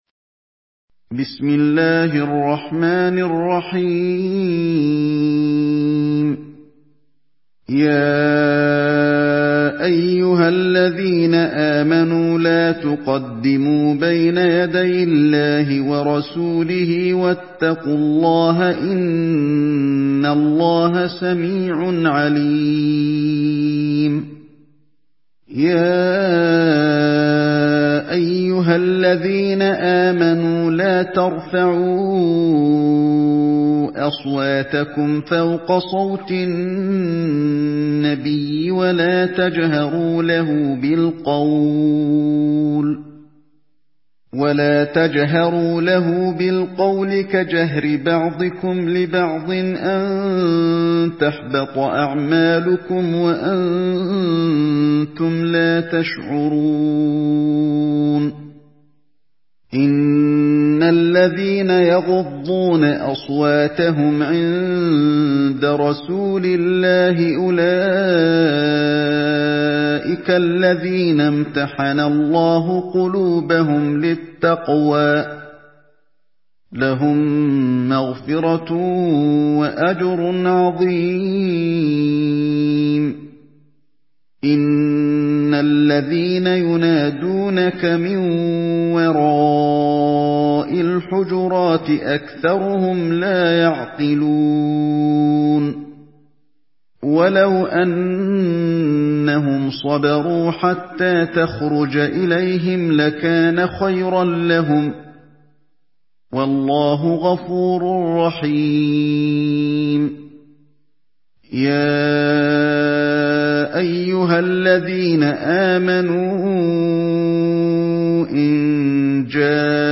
Surah الحجرات MP3 in the Voice of علي الحذيفي in حفص Narration
مرتل